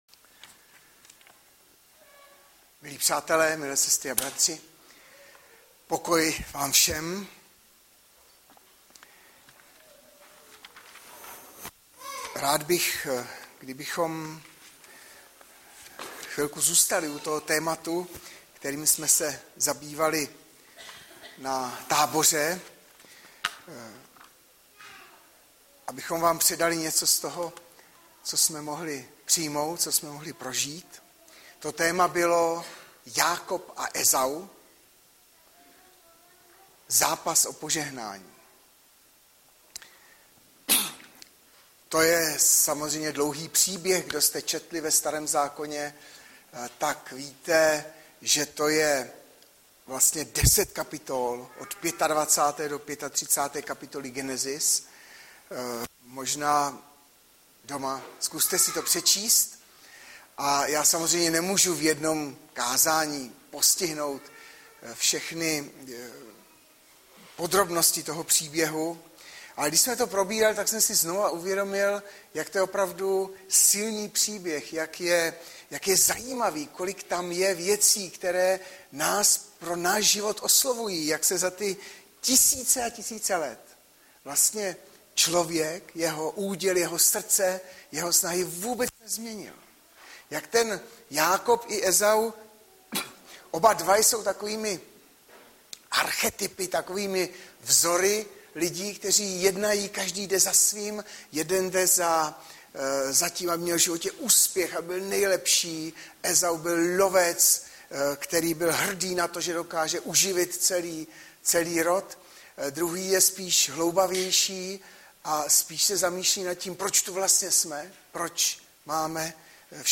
Kázání
Audiozáznam kázání si můžete také uložit do PC na tomto odkazu.